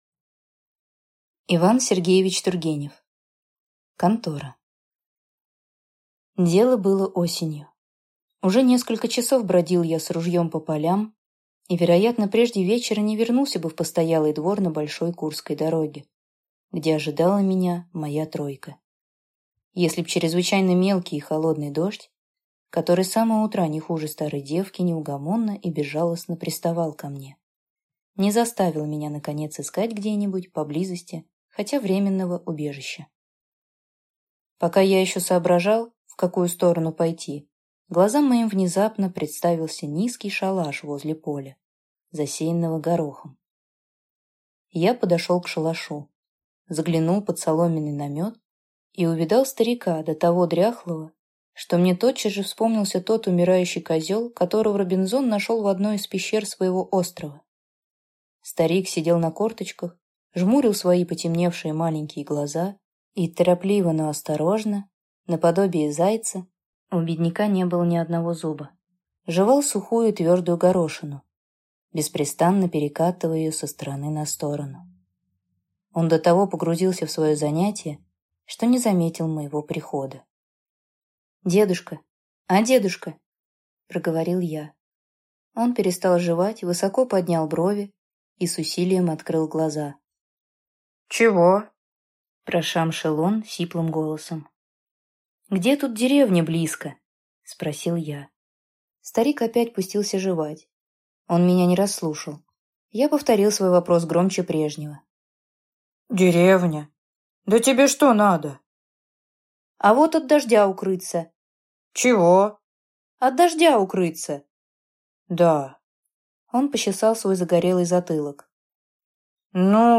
Аудиокнига Контора | Библиотека аудиокниг